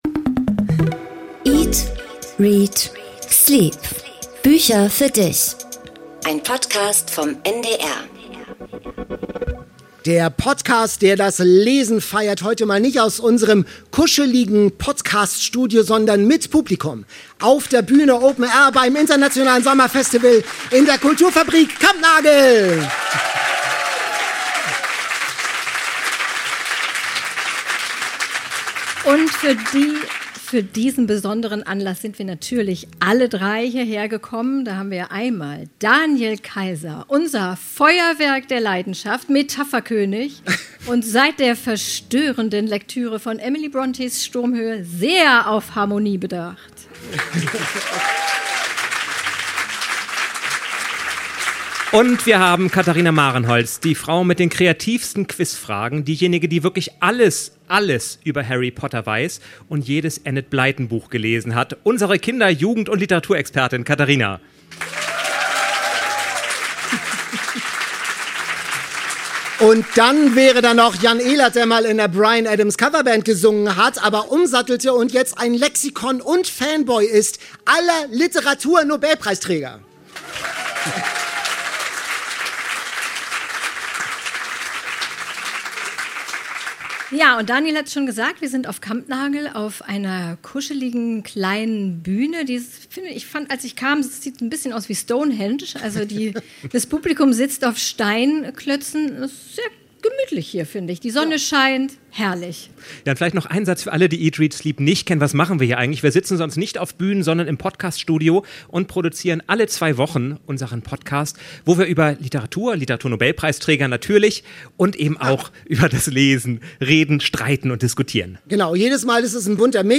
Diesmal mit Live-Publikum auf Kampnagel in Hamburg: Whisky auf der Bühne, Klassiker-Leidenschaft und Quiz-Drama An einem lauen Sommerabend fand die erste öffentliche Podcast-Aufzeichnung statt.